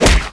空手击中4zth070522.wav
通用动作/01人物/03武术动作类/空手击中4zth070522.wav